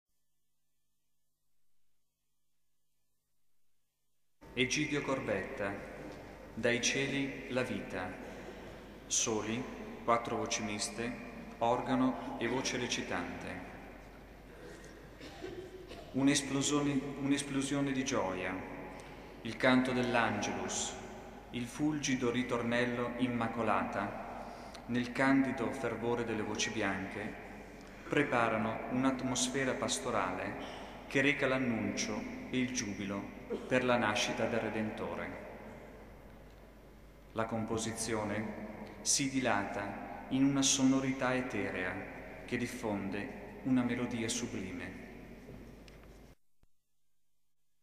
Elevazioni Musicali > 1995 > 1999
S. Alessandro in Colonna